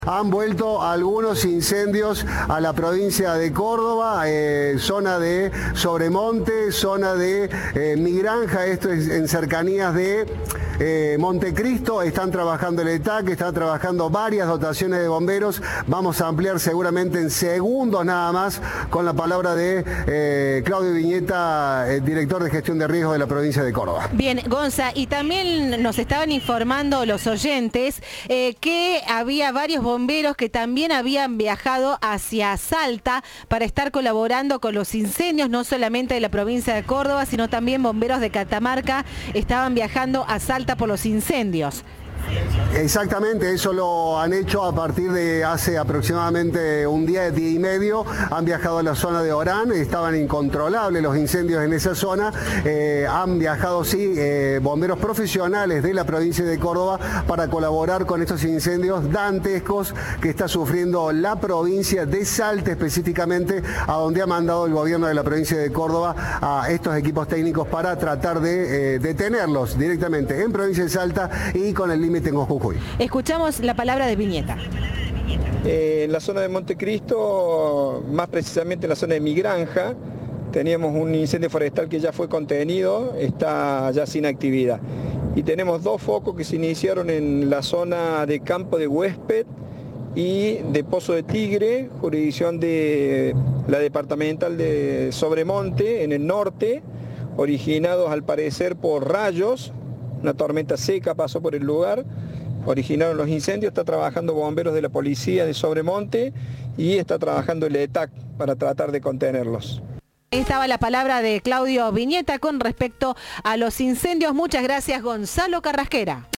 El director de la Secretaría de Riesgo climático y Protección Civil de la Provincia, Claudio Vignetta, dijo a Cadena 3 que las llamas fueron originadas, al parecer, por rayos.